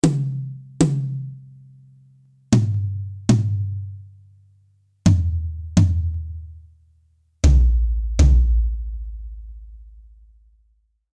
Drum-Tuning
Offener, lang ausklingender Sound mit satten Attack
Hierzu verwendet man vorzugsweise einlagige, durchsichtige Felle wie z.B. Remo Ambassador, Evans Genera G1 oder ähnliche.
dt_tom01.mp3